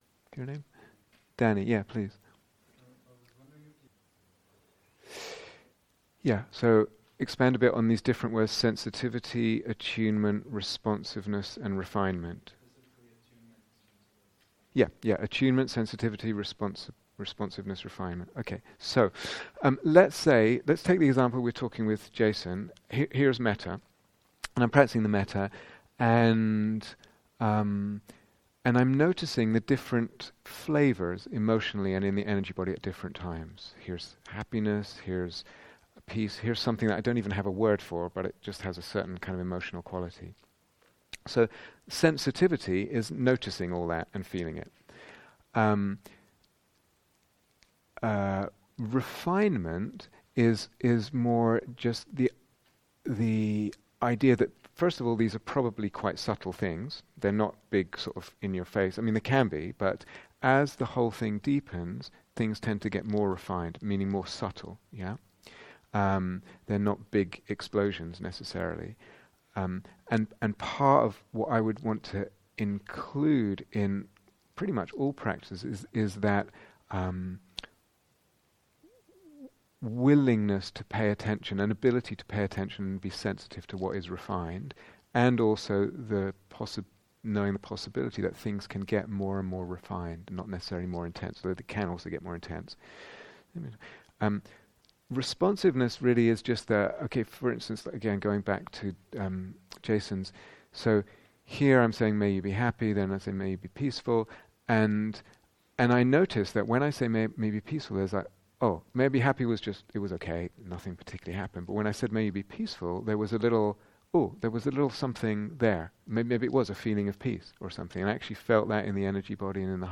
Q & A